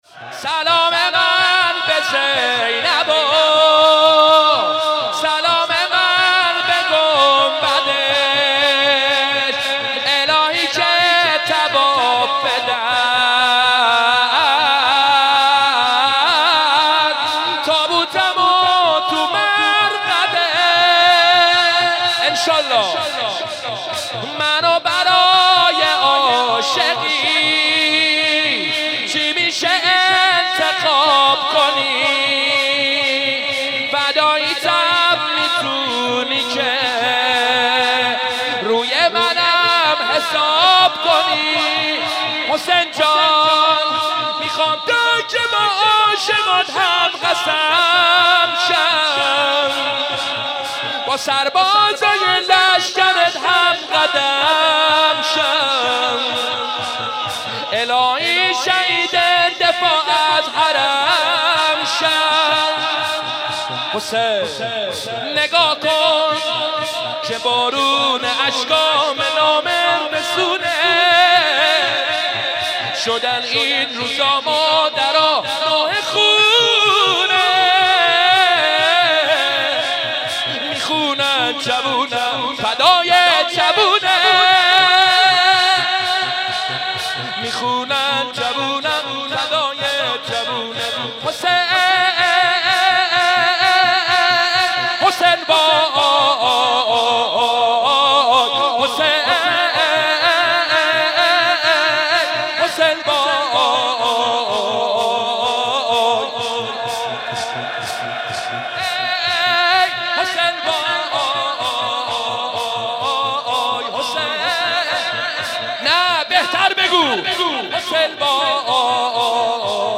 25 ماه مبارک رمضان
کی می تونه غیر از تو مرهم غم ها باشه/شور